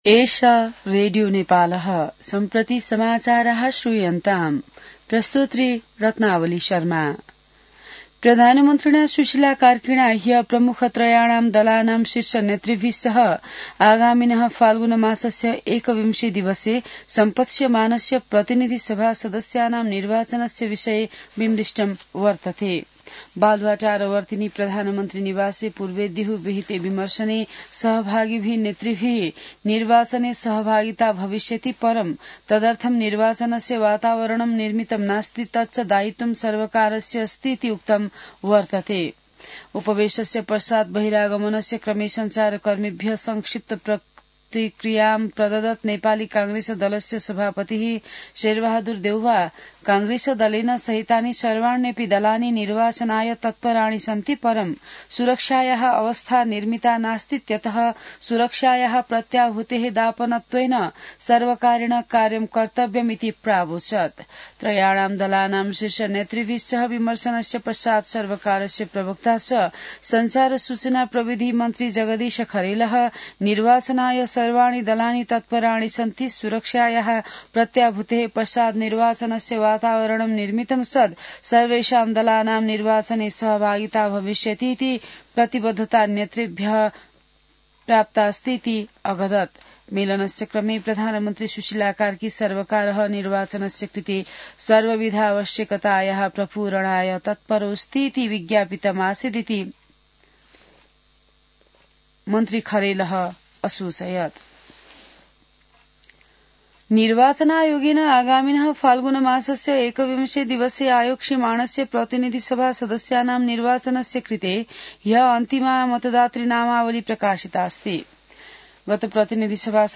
संस्कृत समाचार : १३ पुष , २०८२